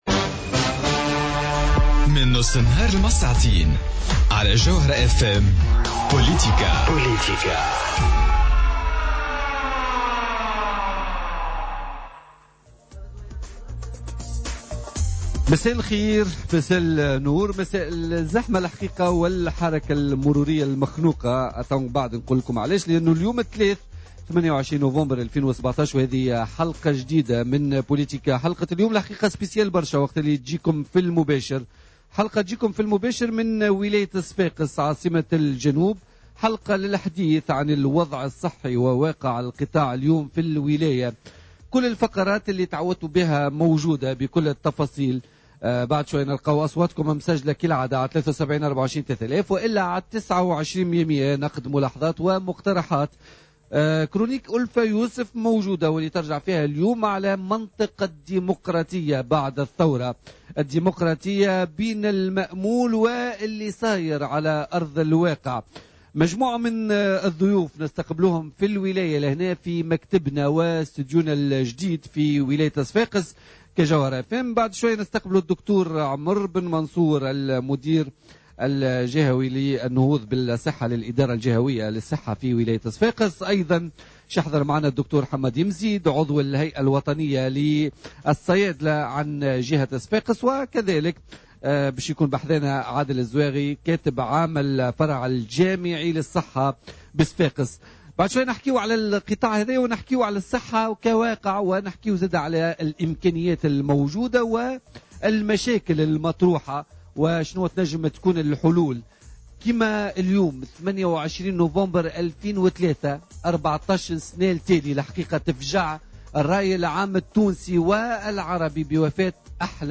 حصة خاصة من مدينة صفاقس